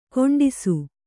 ♪ koṇḍisu